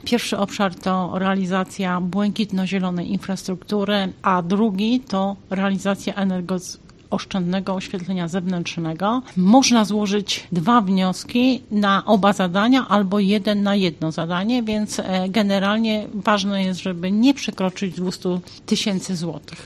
O obszarach dofinansowania mówi Członek Zarządu Województwa Mazowieckiego, Janina Ewa Orzełowska: